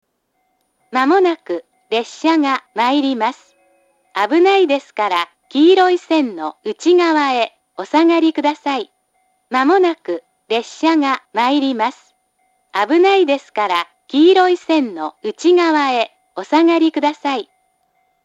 発車ベルはありませんが、接近放送があります。
放送前のチャイムは流れず、いきなり放送が始まります。
かつては遠隔の詳細放送と従来からの簡易放送は異なるスピーカーから流れていましたが、現在は同じスピーカーから流れます。
２番線接近放送
shinano-kizaki-2bannsenn-sekkinn1.mp3